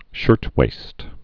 (shûrtwāst)